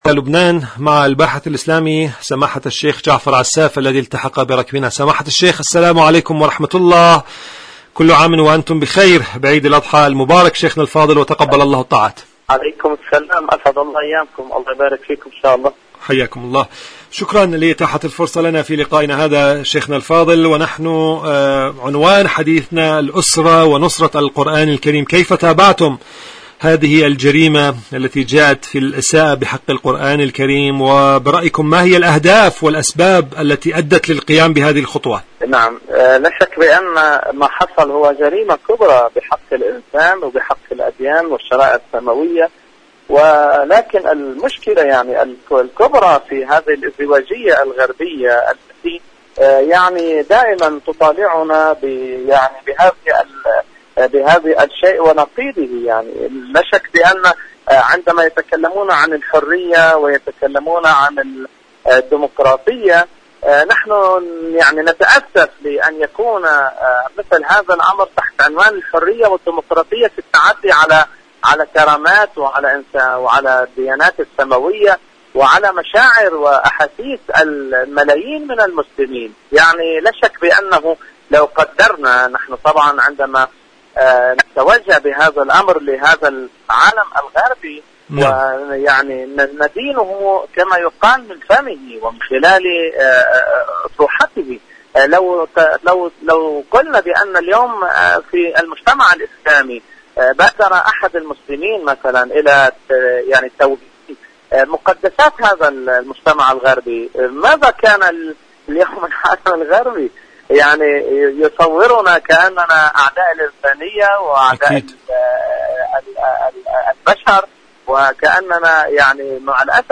إذاعة طهران-معكم على الهواء: مقابلة إذاعية
مقابلات برامج إذاعة طهران العربية برنامج معكم على الهواء مقابلات إذاعية القرآن الكريم الأسرة نصرة القرآن الكريم معكم على الهواء شاركوا هذا الخبر مع أصدقائكم ذات صلة مسيرات يوم القدس العالمي، الرسائل والتداعيات..